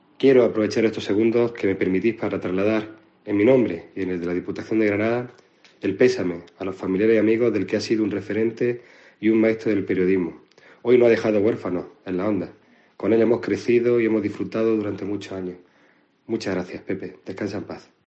Francis Rodríguez, Presidente de la Diputación